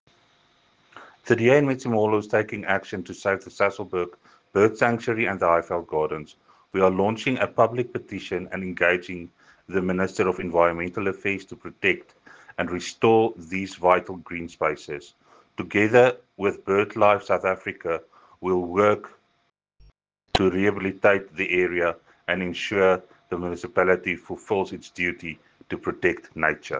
Afrikaans soundbites by Cllr Jacques Barnard and